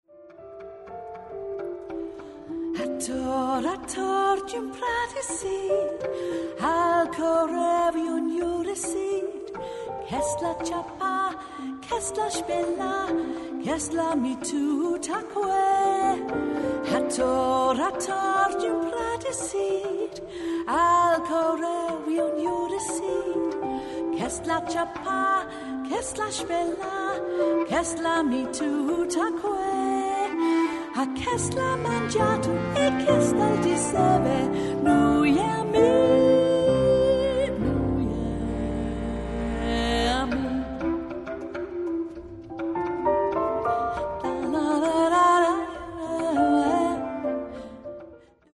Recorded December 2012, Auditorio Radiotelevisione, Lugano
Piano
Sop. Sax, Bs Clt